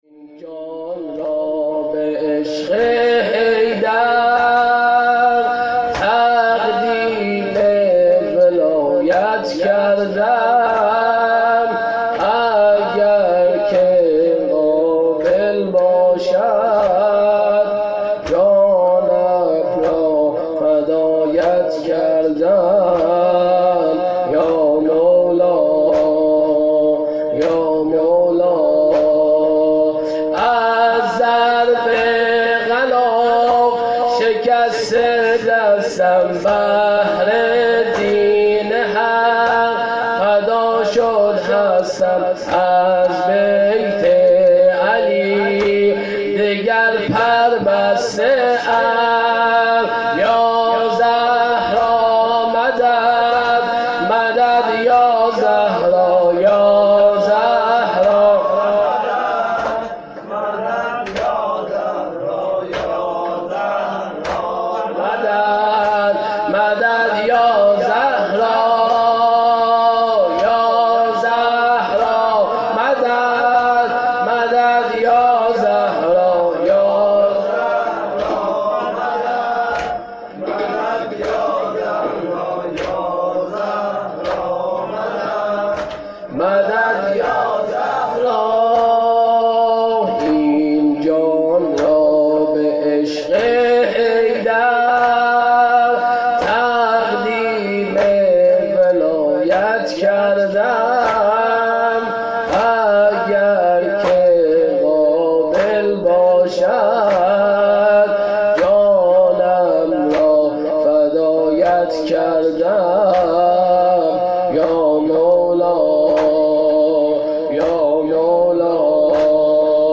هیأت زوارالزهرا سلام اللّه علیها
فاطمیه دوم شب دوم 96